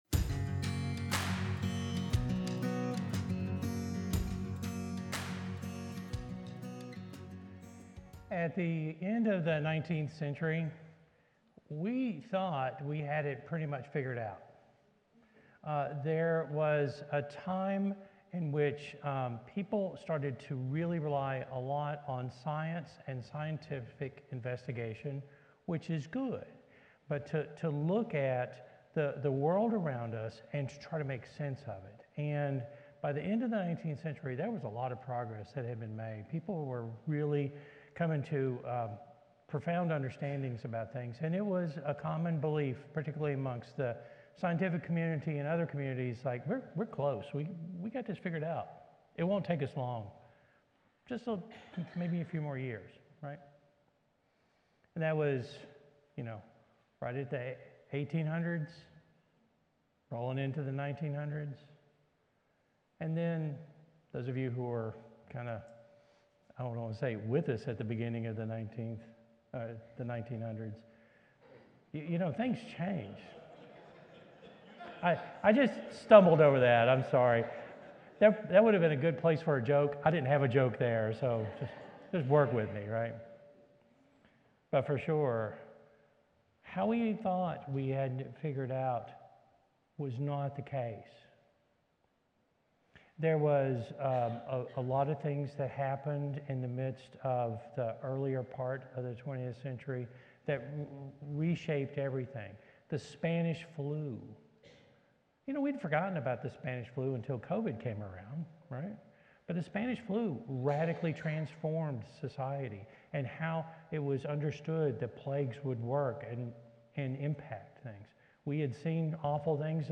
He encourages believers to find joy in the Lord, practice gentleness, and remember God's nearness. The sermon emphasizes the futility of worry and the power of prayer in accessing God's peace that surpasses understanding.